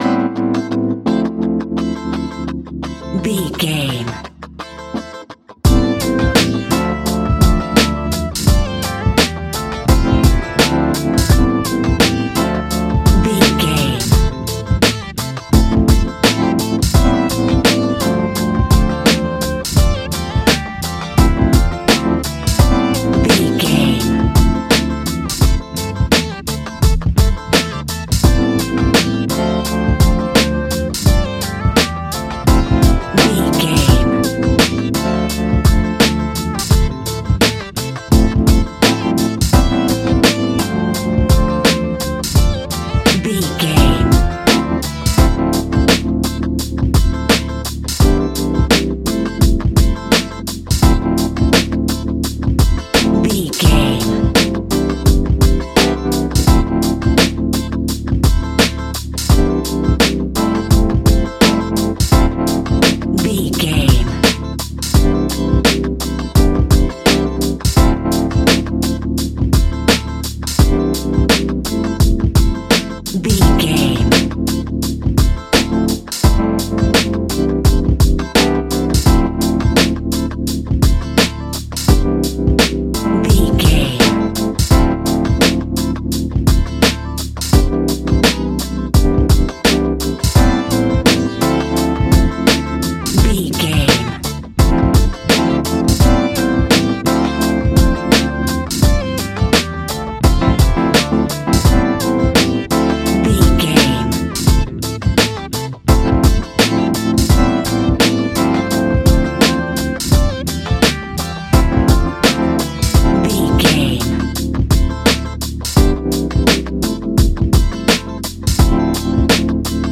Ionian/Major
chilled
laid back
Lounge
sparse
new age
chilled electronica
ambient
atmospheric
morphing
instrumentals